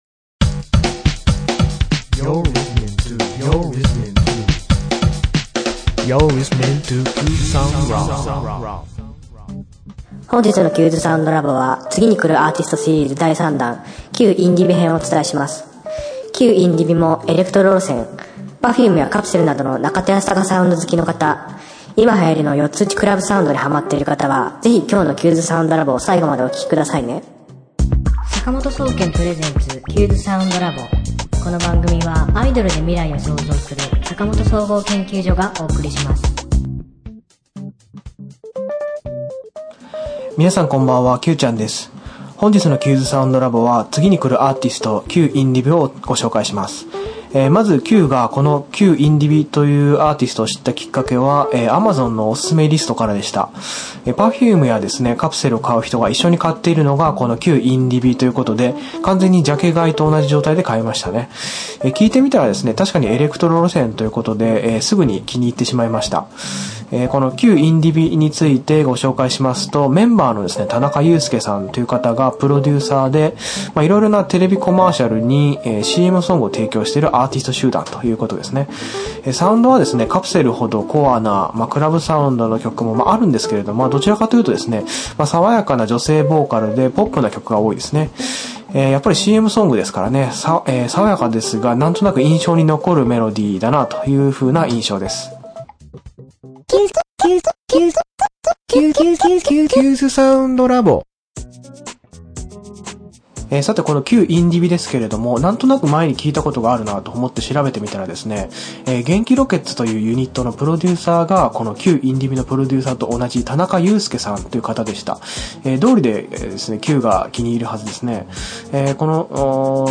今週の挿入歌